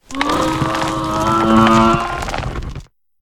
Cri de Craparoi dans Pokémon HOME.